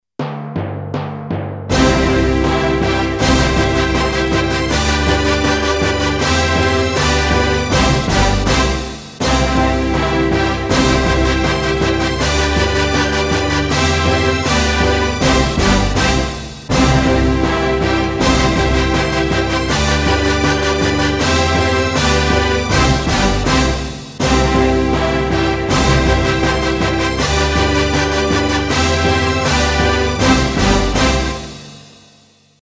Звуки награждений, побед
Торжественный звук награждения с барабанами и трубой